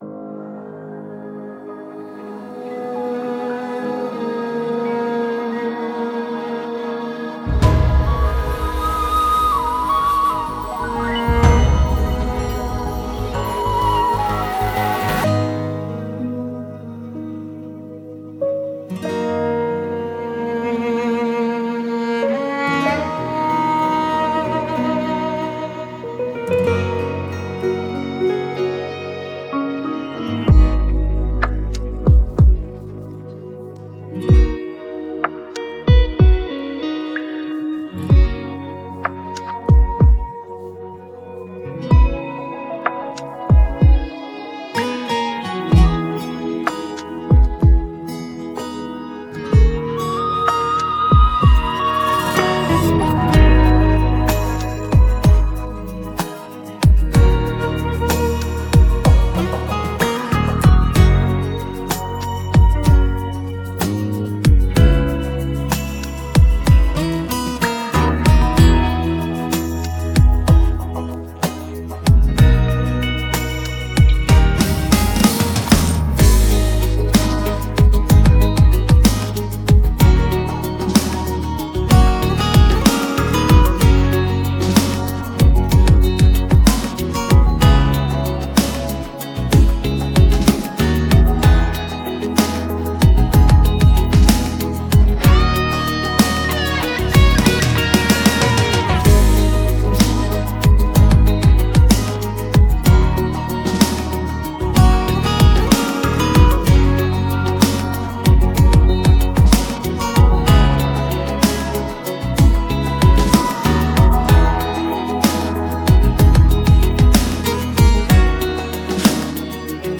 بیت بدون صدا خواننده